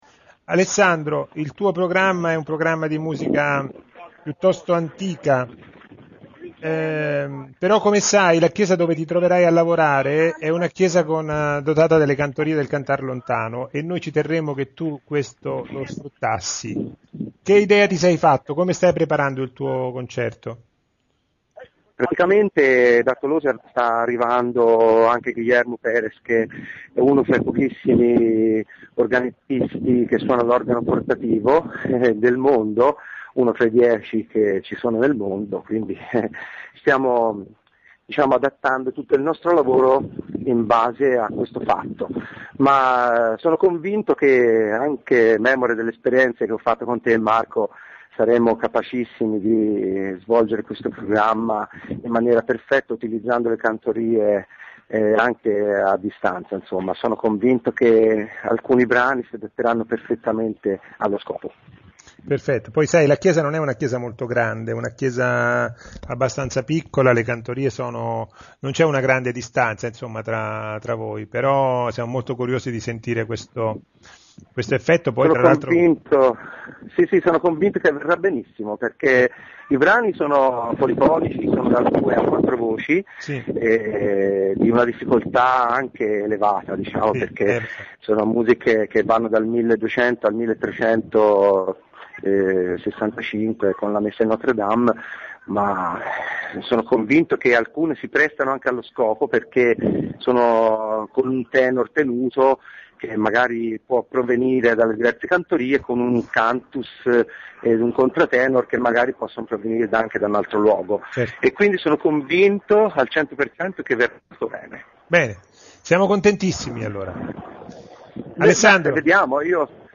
Le interviste agli artisti 2007
Ecco le interviste in formato mp3, concerto per concerto: